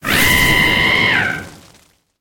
Cri de Pyrobut dans Pokémon HOME.